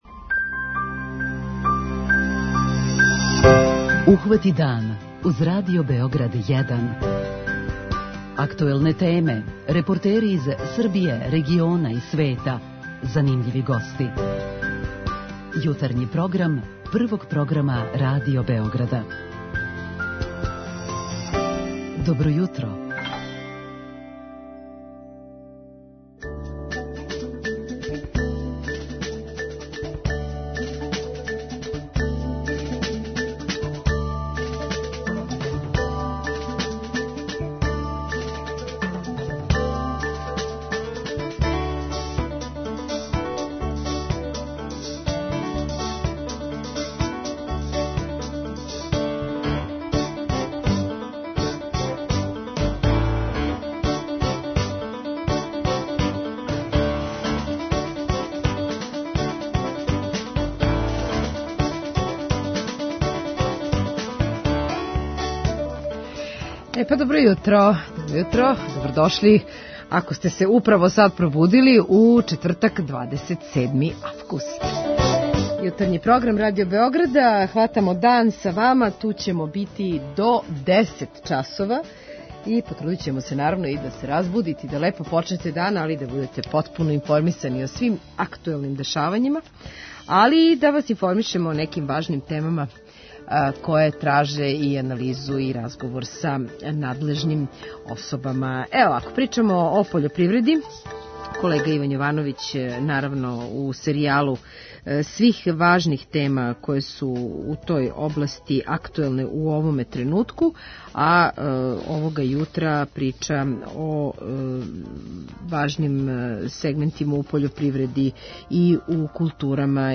преузми : 35.15 MB Ухвати дан Autor: Група аутора Јутарњи програм Радио Београда 1!